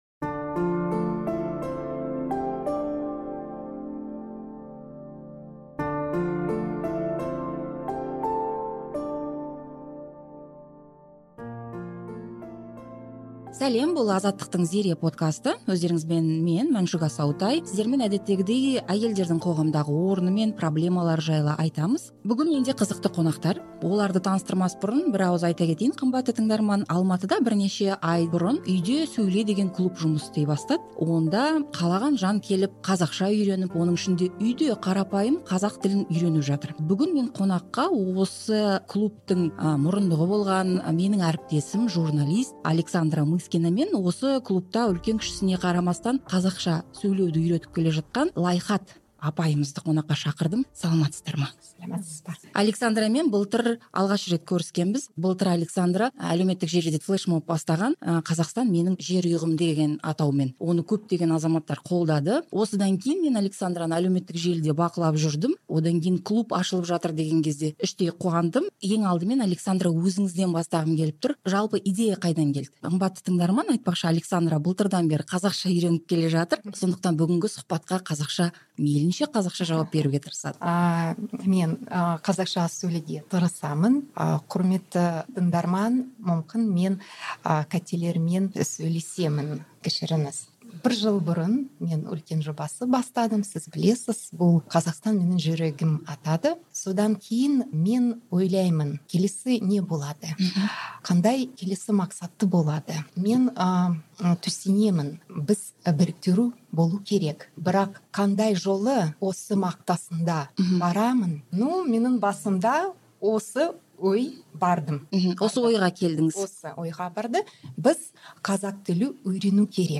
Жертөледегі аядай бөлмеге он шақты адам жиналып, дастарқан жайып, шайға отырды.